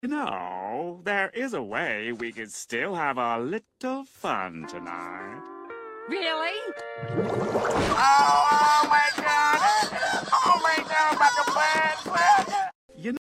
Follow for more deep fried FreakBob sound effects free download